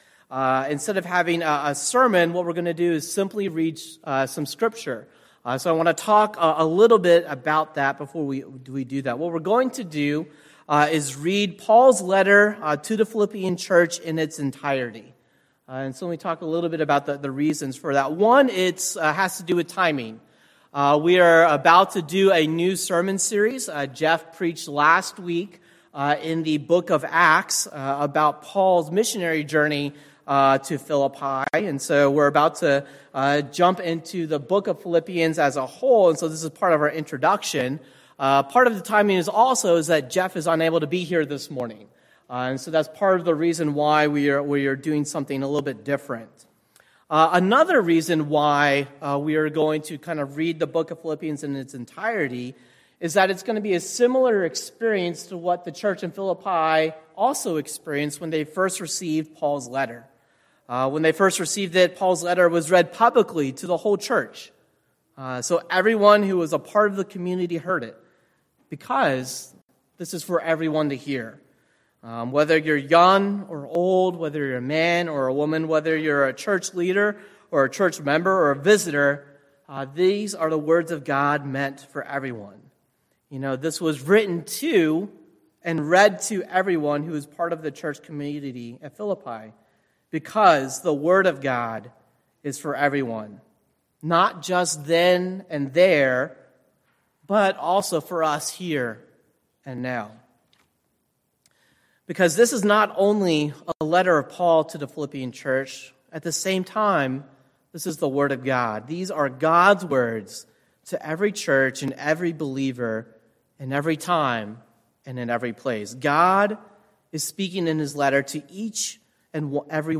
Reading of Philippians